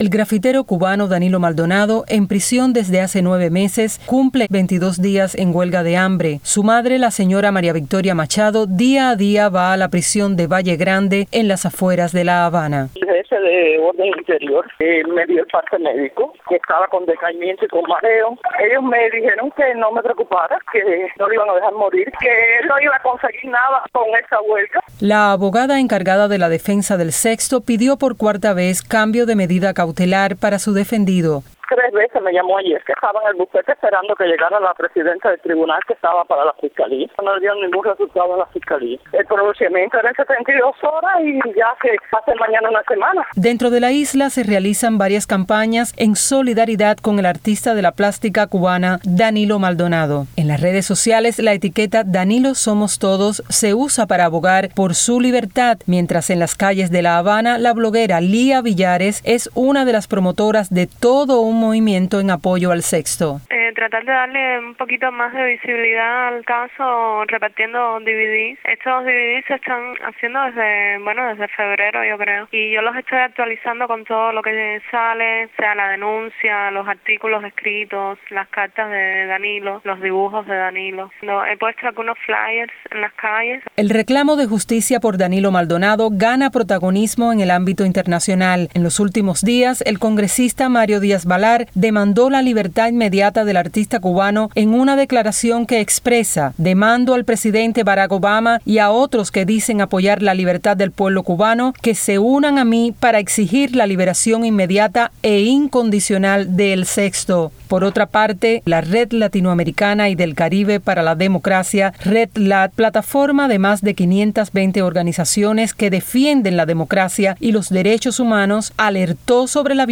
Detalles en este reportaje